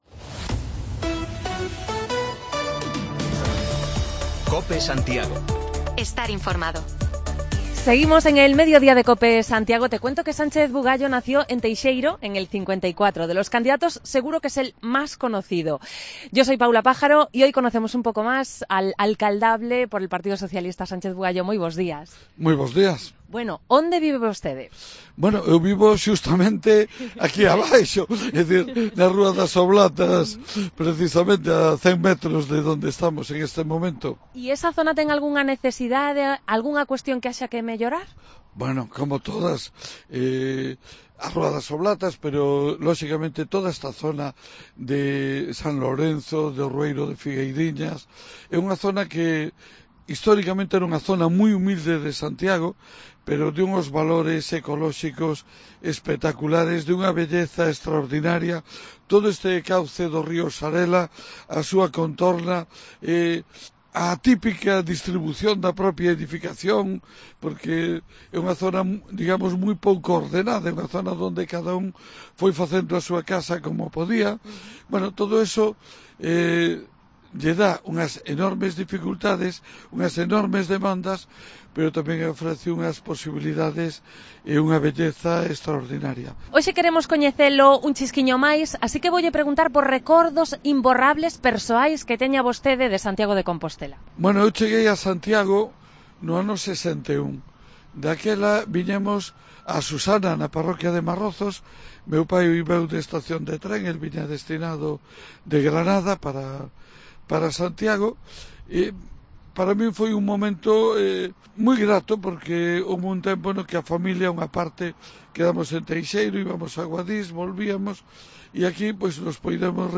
Terminamos las entrevistas de proximidad con los principales candidatos a la alcaldía de Santiago charlando con el candidato a la reelección, el socialista Sánchez Bugallo. Nos contó desde alguna fobia alimentaria hasta sus deseos de cara al futuro